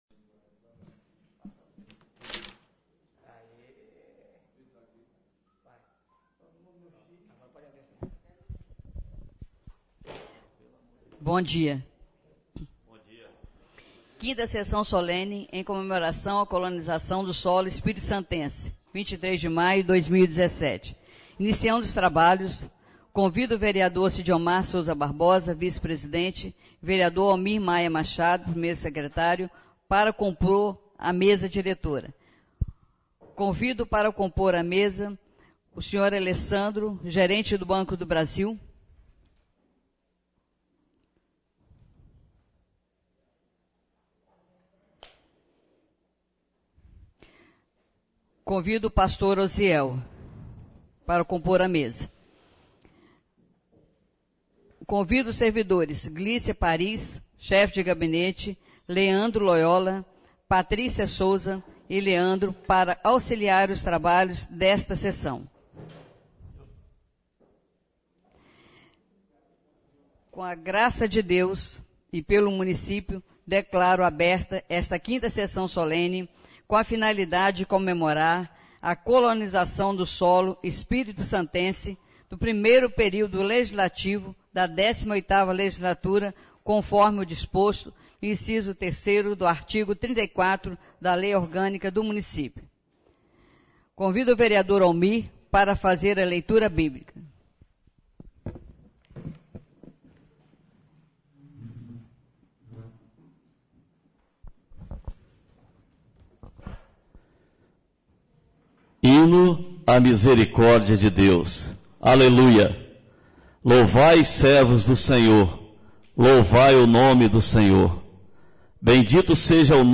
SESSÃO SOLENE COLONIZAÇÃO DO SOLO 23 DE MAIO DE 2017